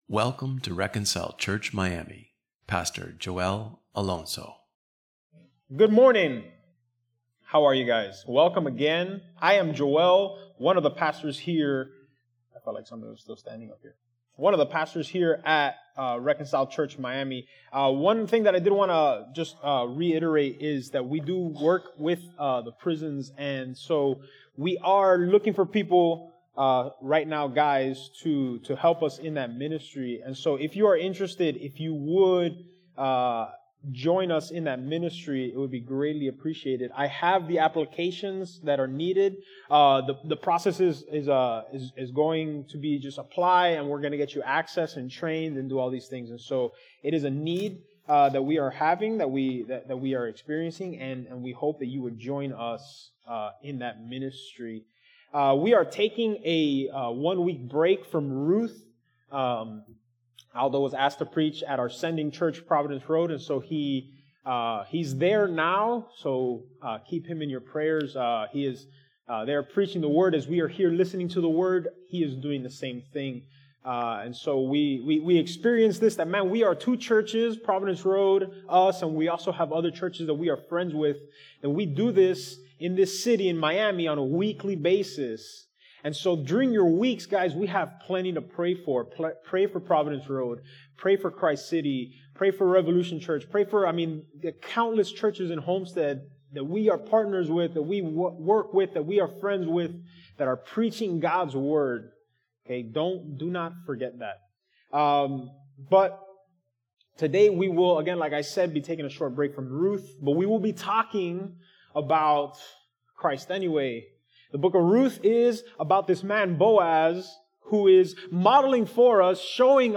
Matthew 6:25-30 Independent Sermon Series Delivered on